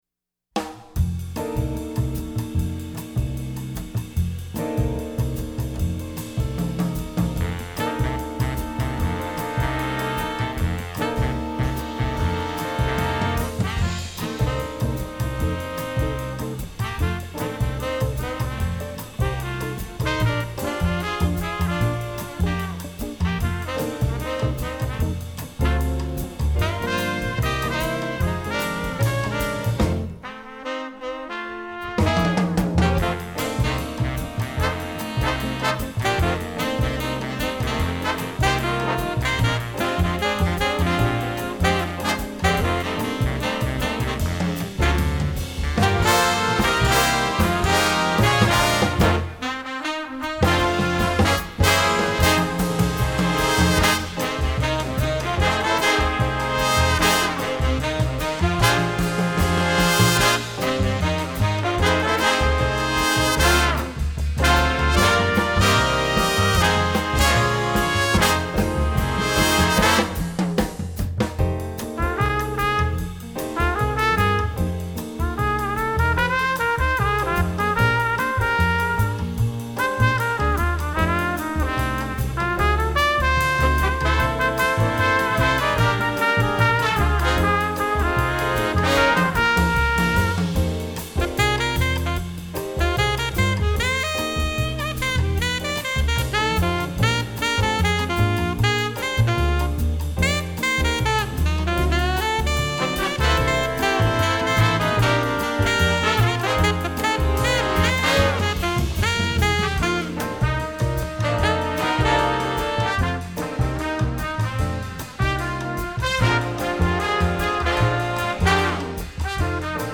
Gattung: für Jazz Ensemble
Besetzung: Jazz-Ensemble